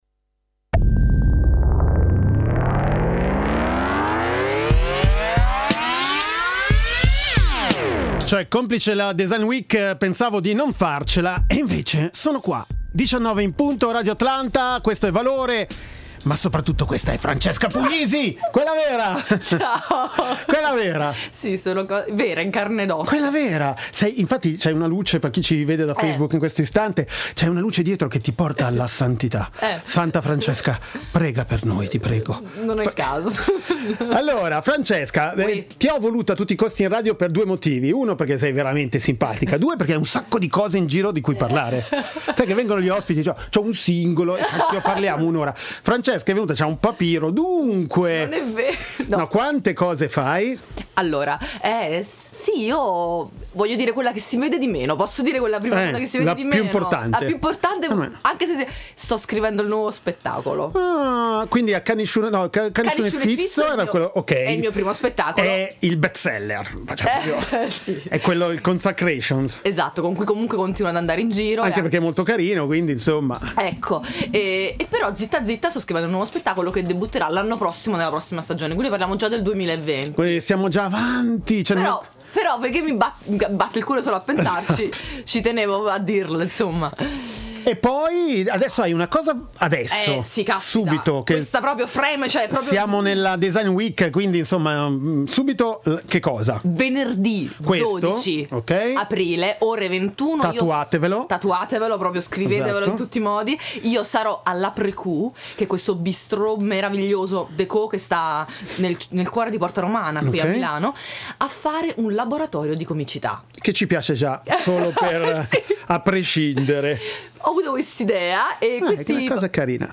Musica e parole al servizio di pensieri in libertà, si passa dal rock al cantautorato. La cosa che conta è che ci sia una storia da raccontare, un’emozione da passare.